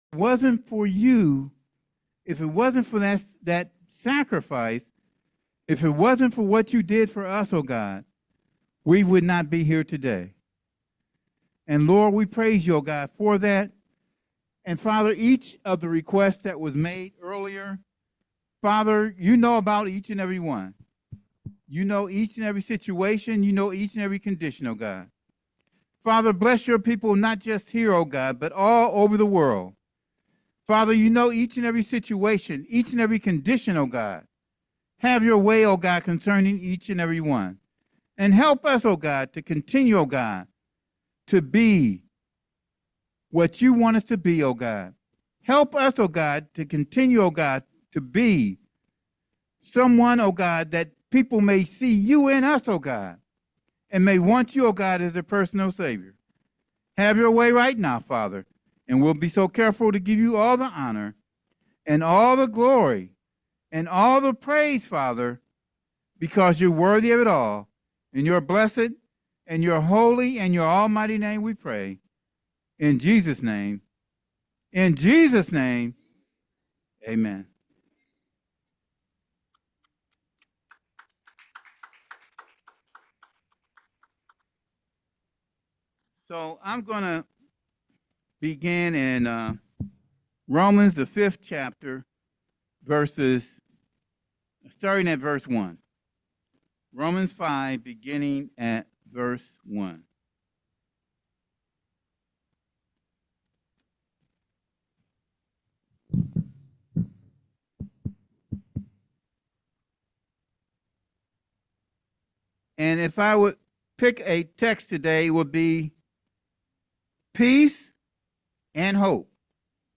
Sermon Handout